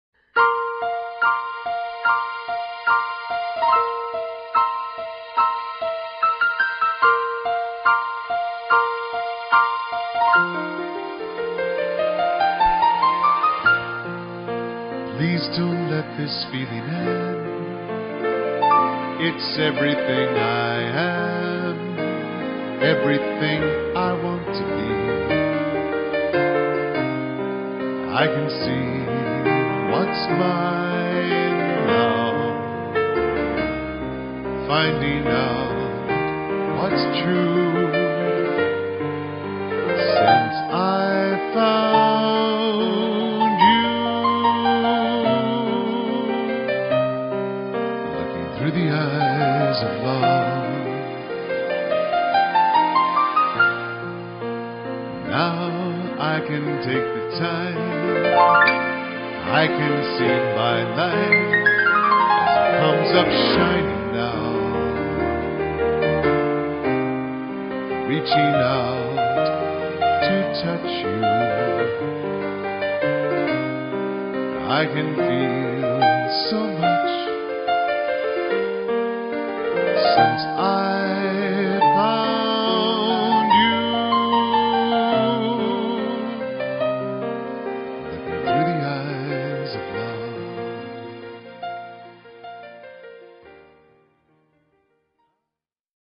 Contemporary Ballad